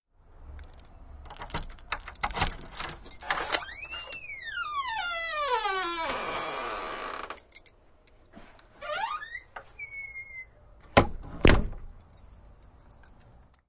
音效 " 前门打开 外面
描述：前门用耶鲁钥匙打开，外面打开，里面关闭。